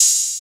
808 Mafia Open Hat Edited.wav